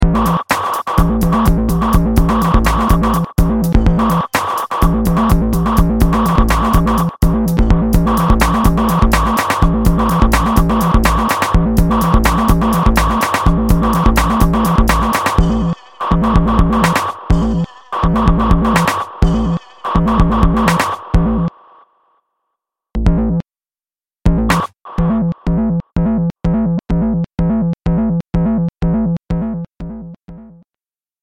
distinctive and attention commanding drum loop
Over 200 loops of off-the-planet grooves to take